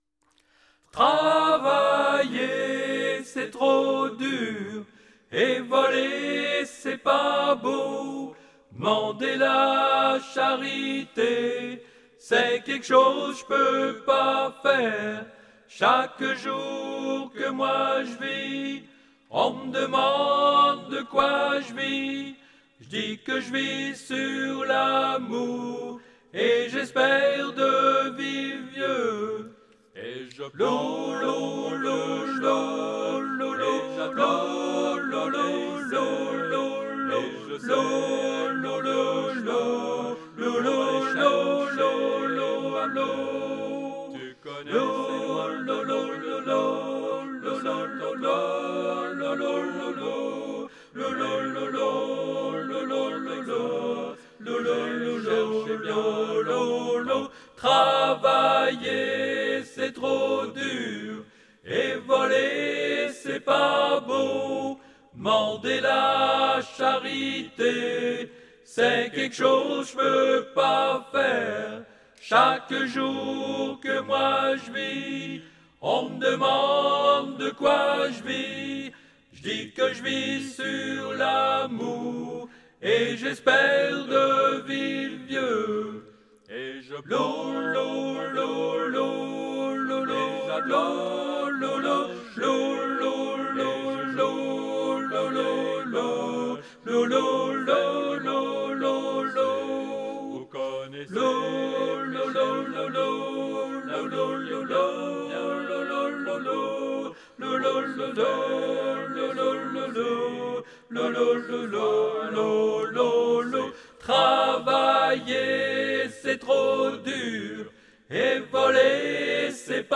traditionnel cajun
Ténor